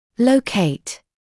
[ləu’keɪt][лоу’кейт]локализовать; находить местонахождение